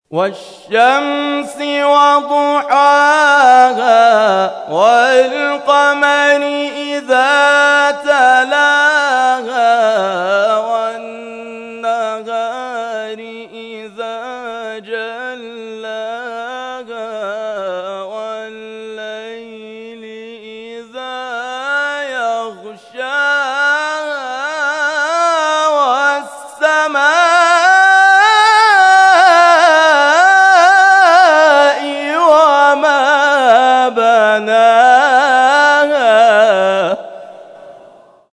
در ادامه قطعات تلاوت این کرسی‌های تلاوت ارائه می‌شود.